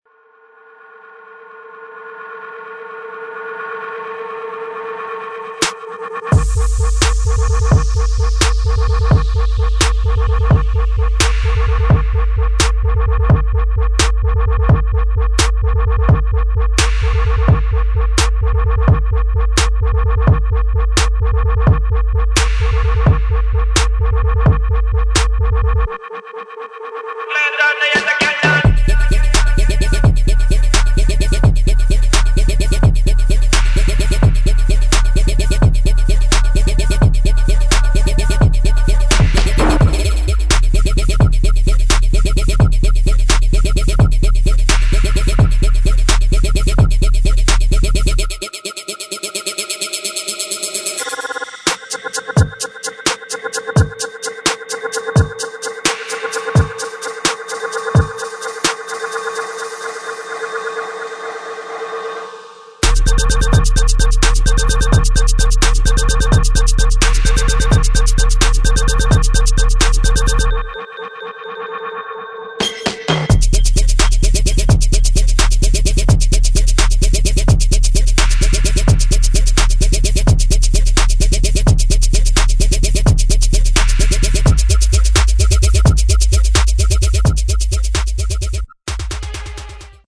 [ DRUM'N'BASS / JUNGLE / JUKE ]